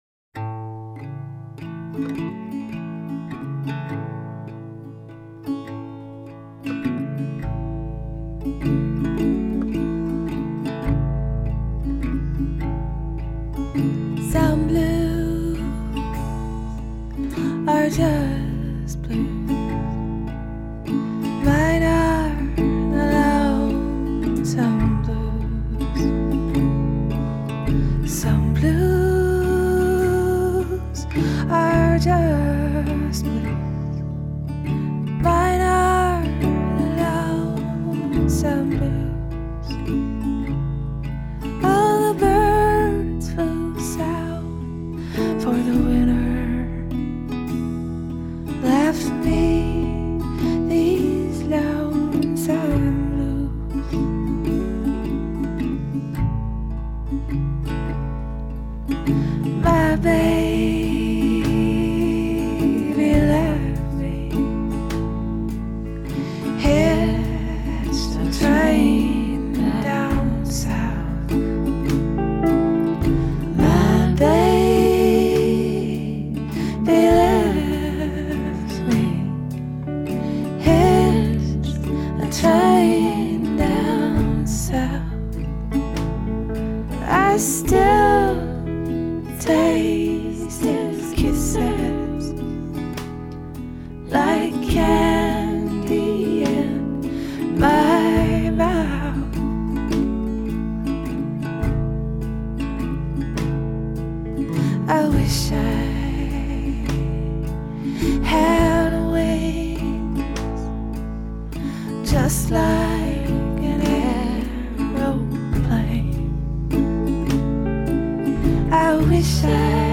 lonesome_blues.mp3